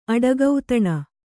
♪ aḍagautaṇa